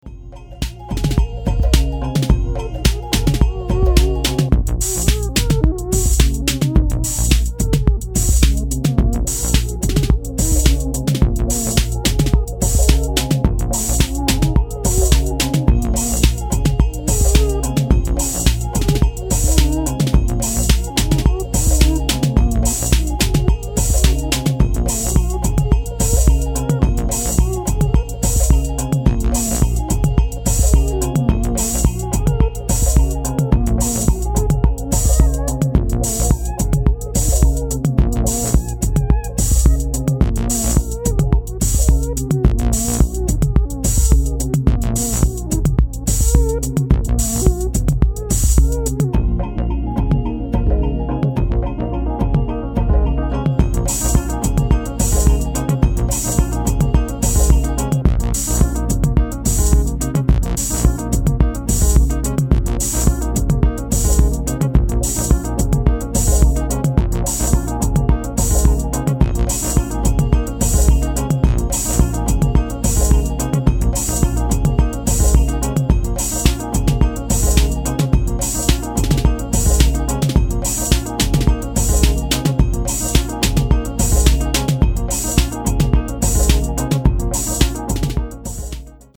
Deeper techno tracks by the macchines of Milano